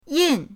yin4.mp3